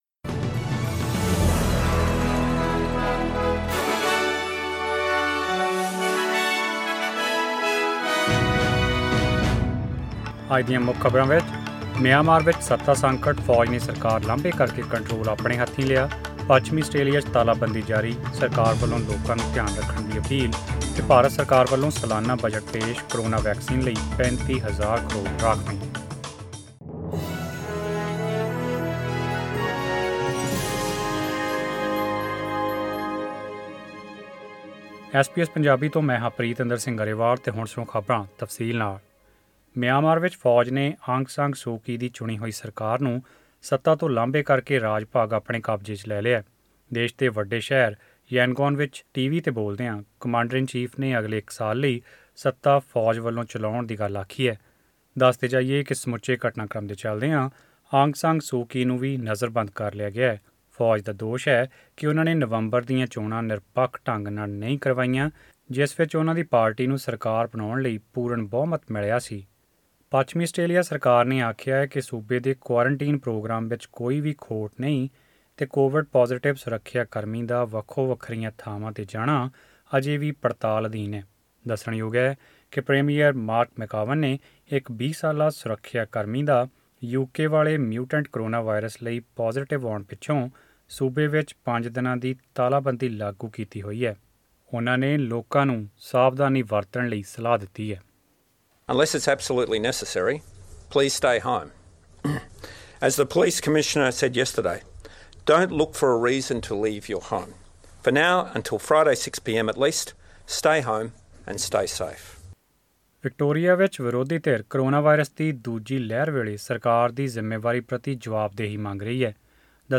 Australian News in Punjabi: 2 Feburary 2021